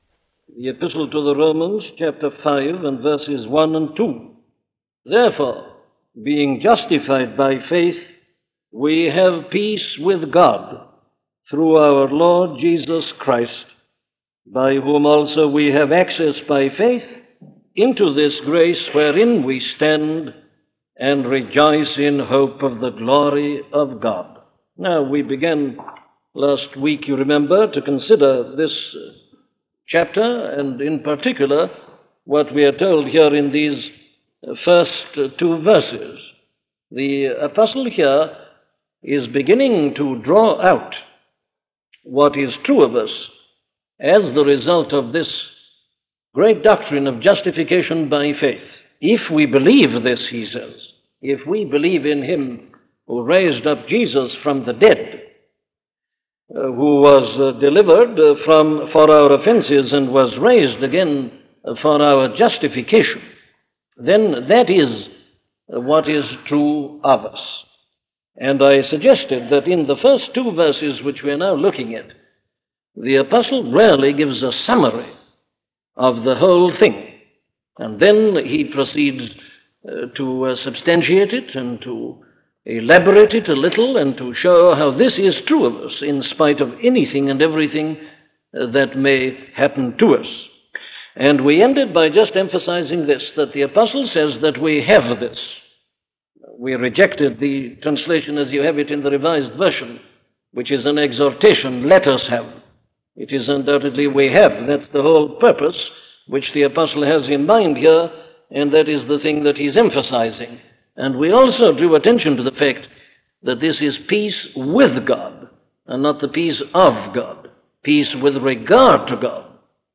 A collection of sermons on Sermons on Peace by Dr. Martyn Lloyd-Jones